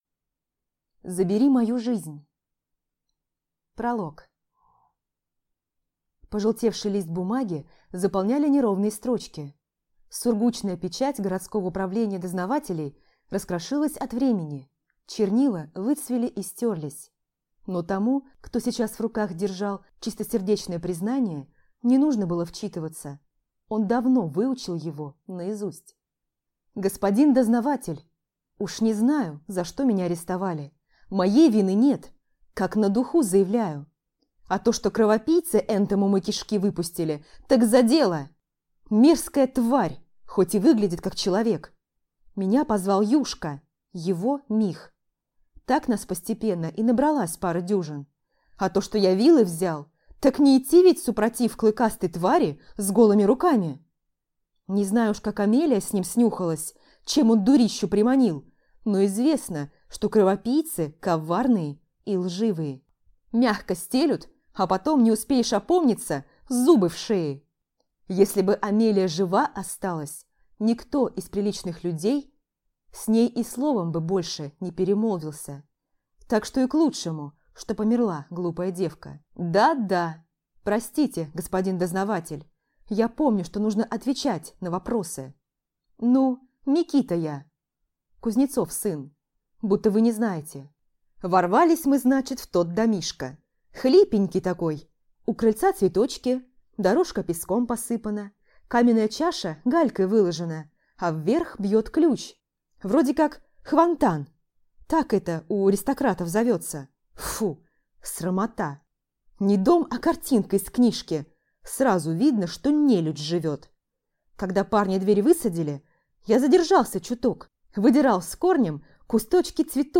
Аудиокнига Забери мою жизнь | Библиотека аудиокниг
Прослушать и бесплатно скачать фрагмент аудиокниги